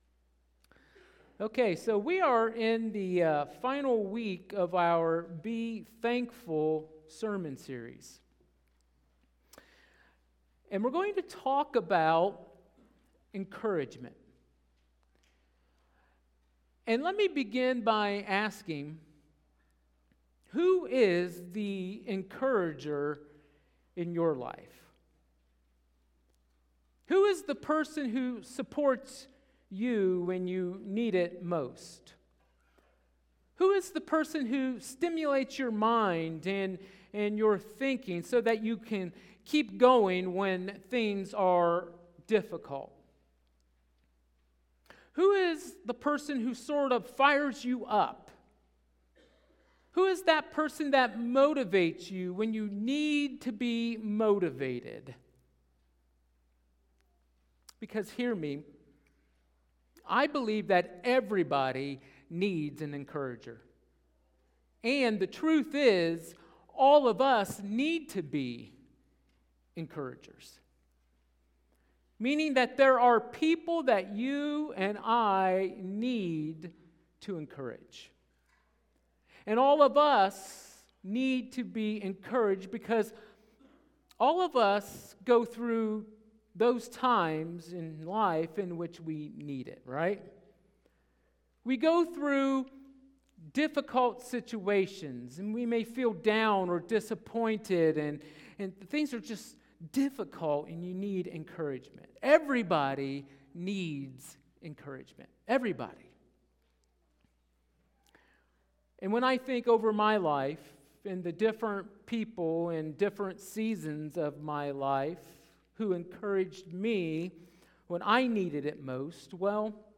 Sermons | Nappanee First Brethren Church